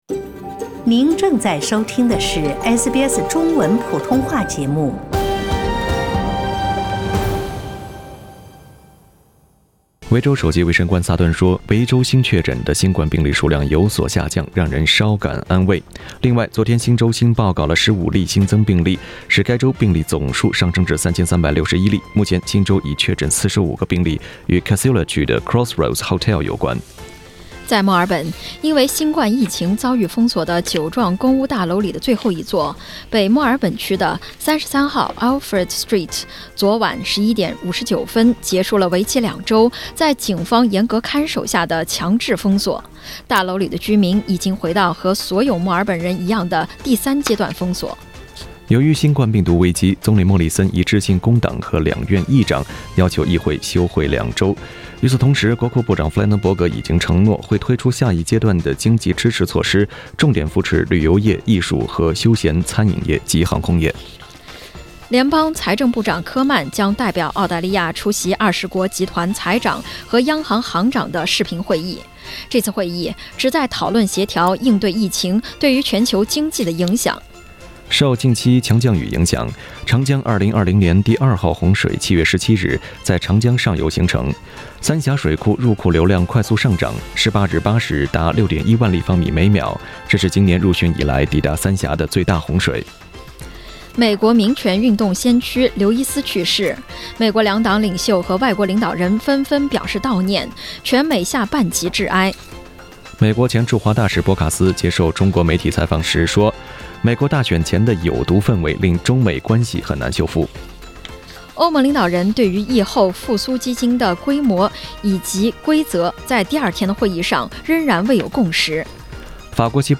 SBS早新聞 (7月19日）